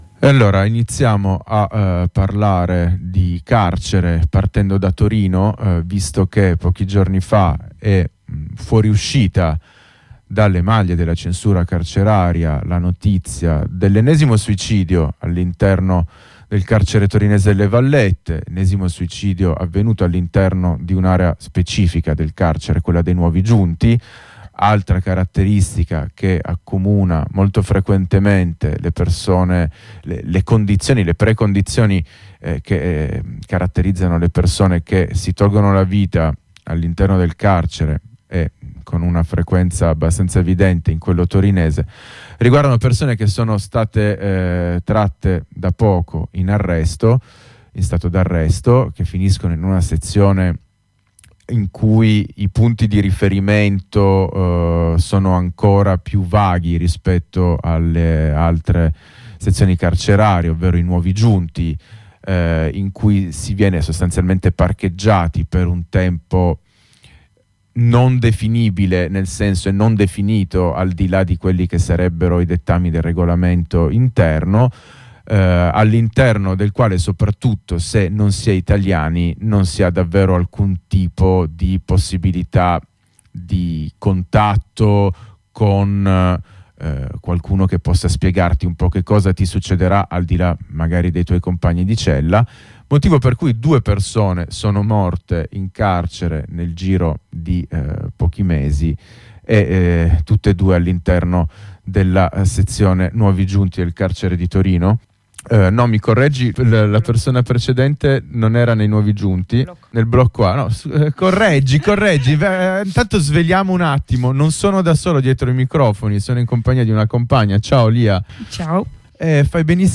Estratti dalla puntata del 31 ottobre 2022 di Bello Come Una Prigione Che Brucia: